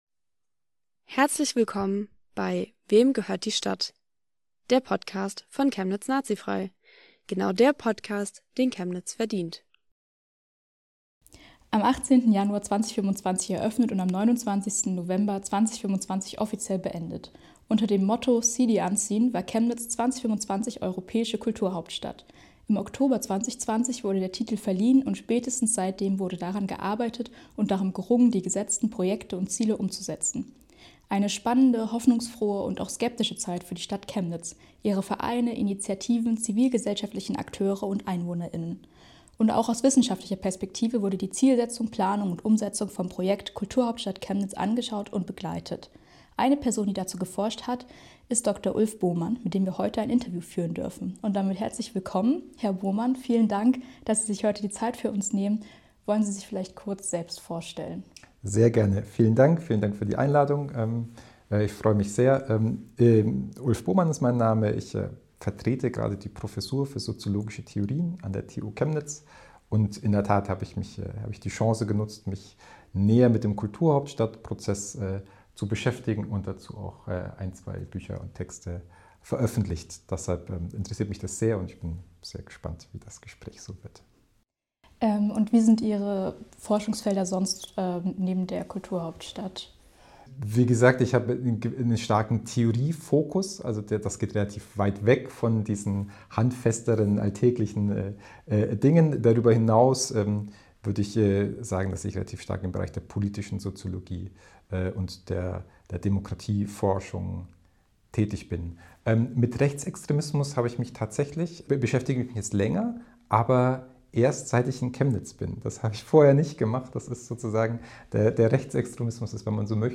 Ein Gespräch über Erwartungen, Entwicklungen und ein Fazit zum besonderen Jahr 2025 in Chemnitz.